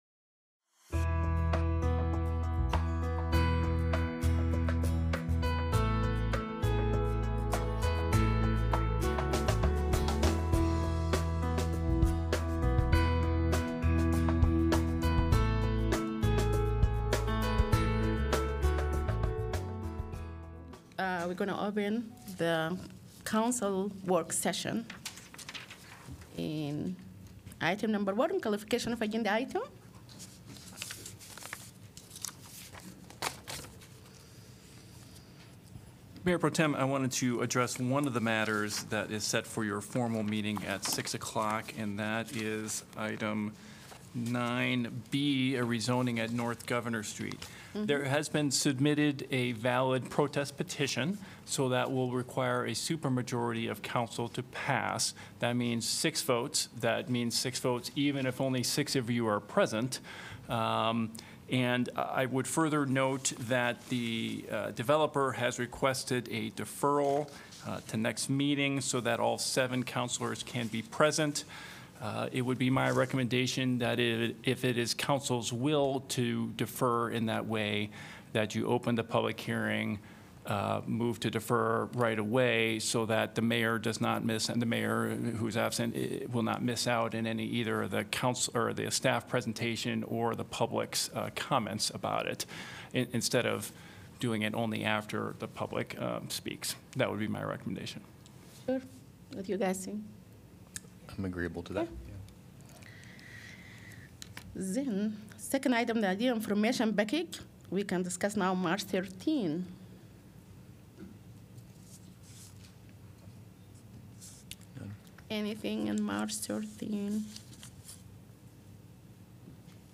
Iowa City City Council Work Session of April 1, 2025
Coverage of the Iowa City Council work session..